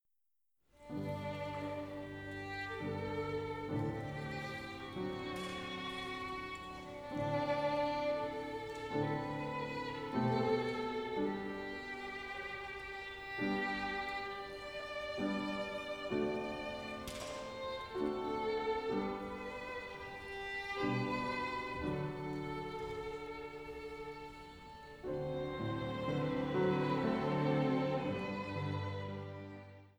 Chor, Orchester